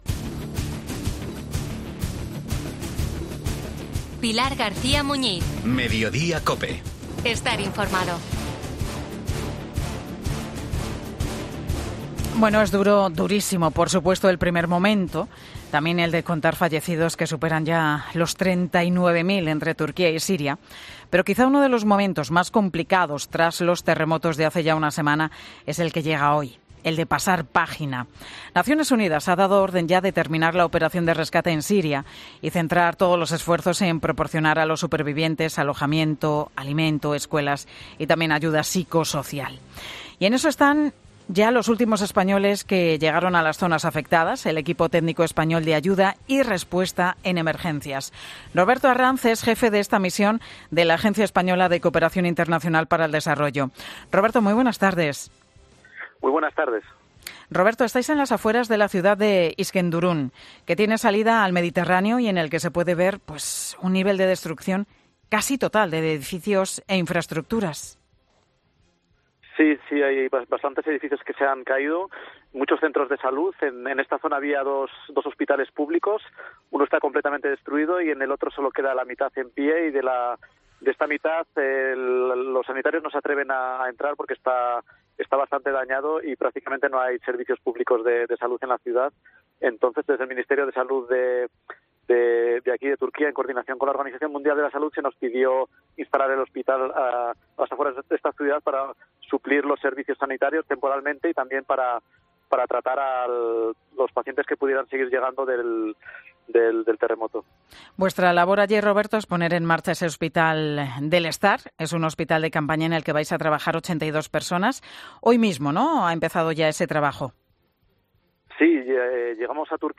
Desde esta misma mañana está operativo en Turquía un equipo técnico español de ayuda.